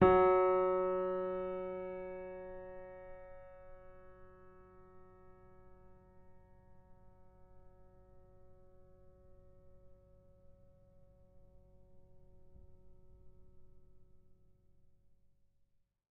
sampler example using salamander grand piano
Fs3.ogg